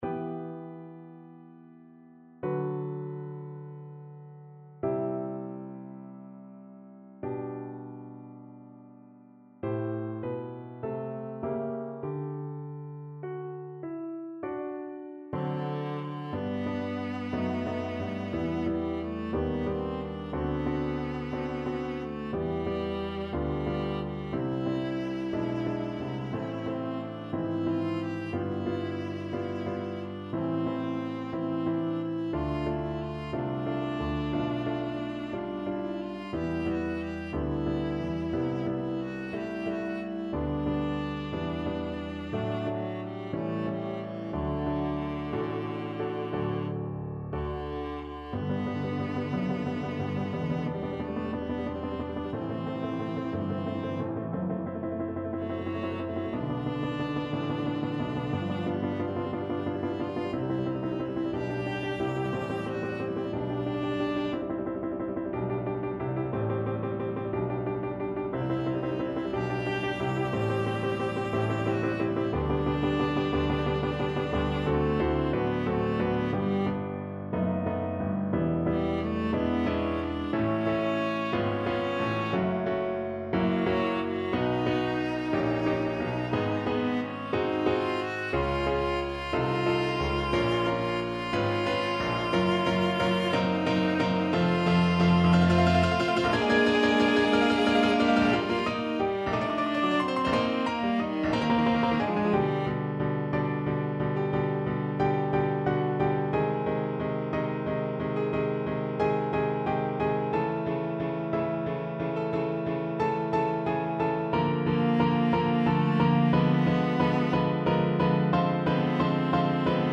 Moderato assai
4/4 (View more 4/4 Music)
Classical (View more Classical Viola Music)